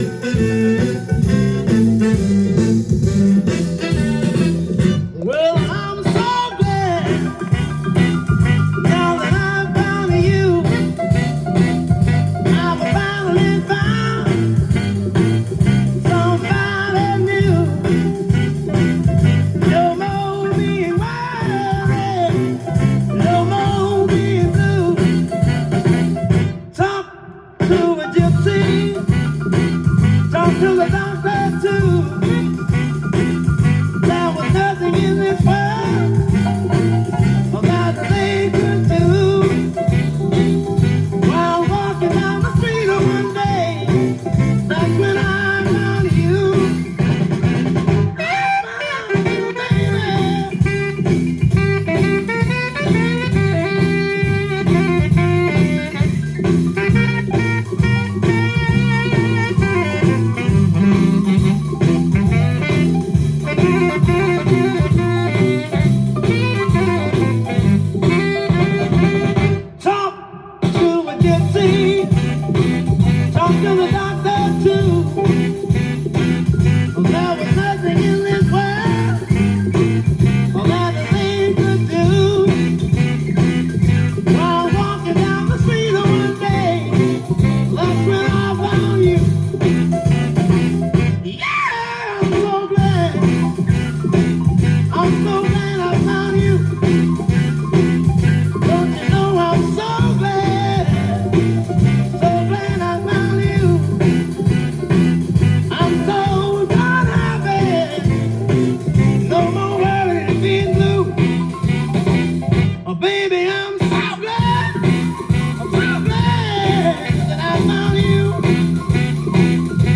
ジャンル：SOUL
LP
店頭で録音した音源の為、多少の外部音や音質の悪さはございますが、サンプルとしてご視聴ください。